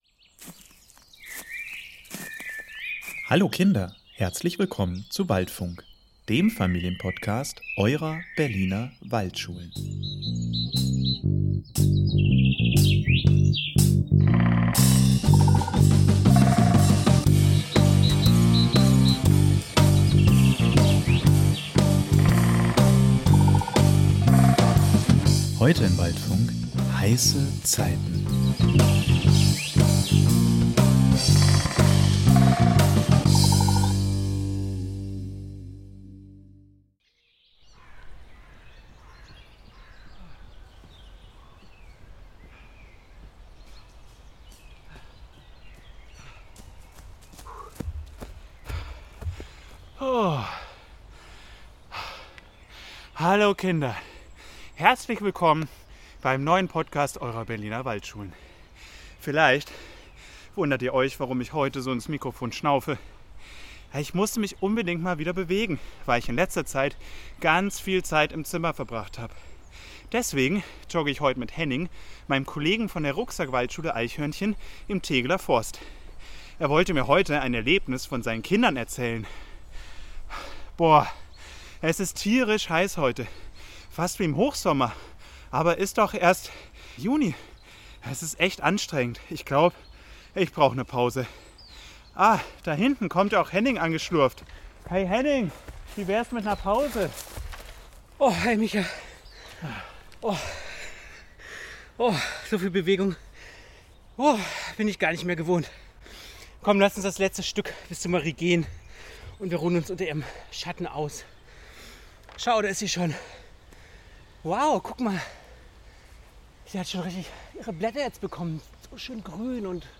im Tegeler Forst und machen eine Pause unter unserer guten alten Marie. Wir strecken gemeinsam unsere Fühler aus und versuchen herauszufinden wie es dem Berliner Wald im Klimawandel so geht. Wie unterstützen die Förster*innen den Wald und seid ihr eigentlich auch schon Klimaschützer?